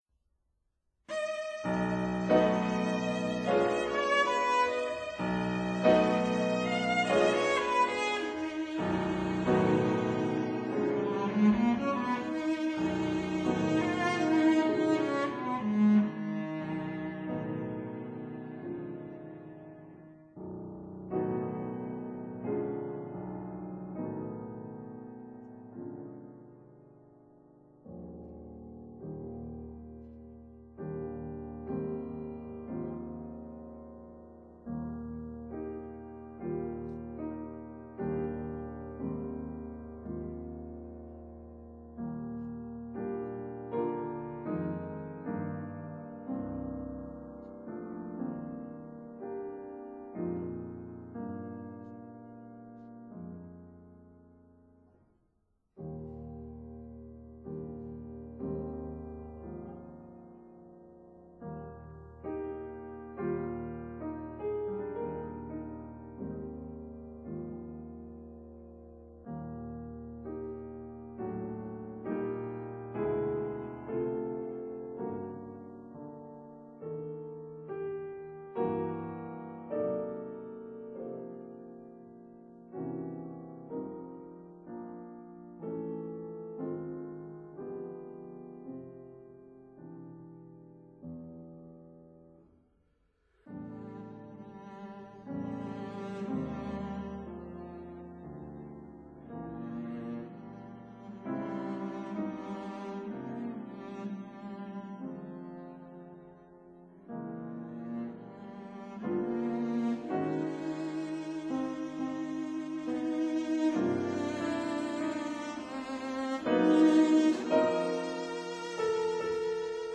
cello
piano